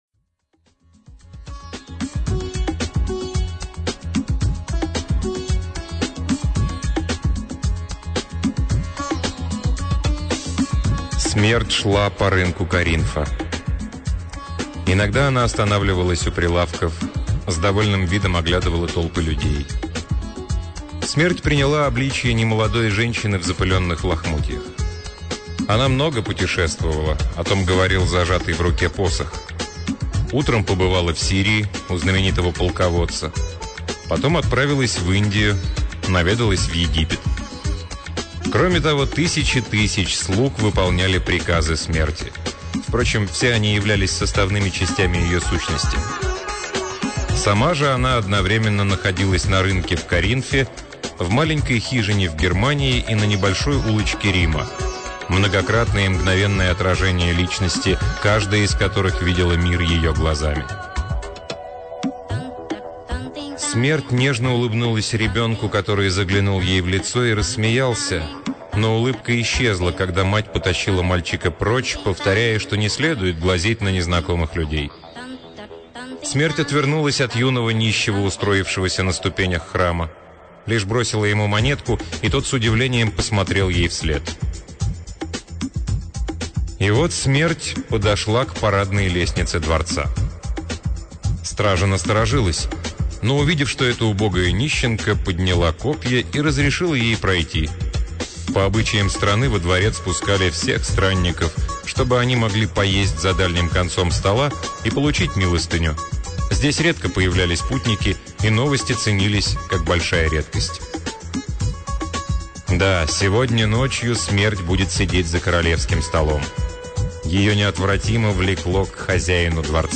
Аудиокнига Кэролин Черри — Повелительница тьмы